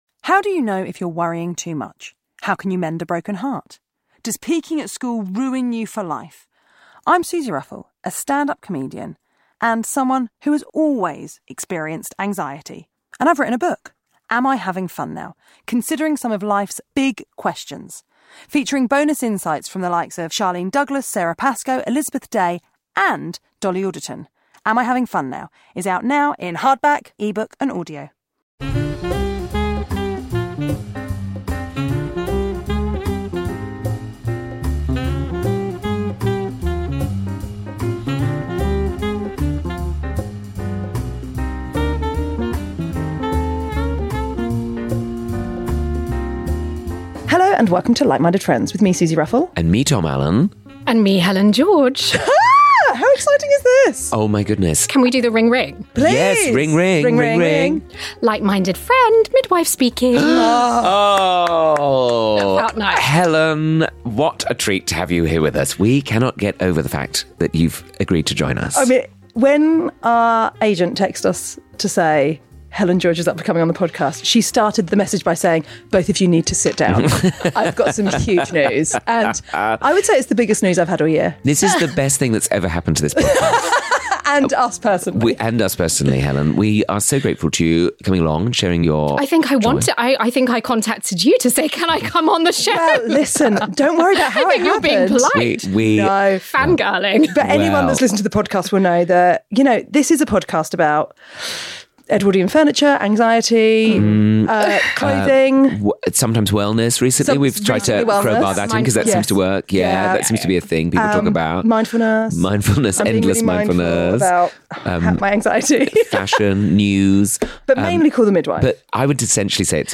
Comedians and dearest pals Tom Allen and Suzi Ruffell chat friendship, love, life and culture....sometimes....
This time with very special guest Helen George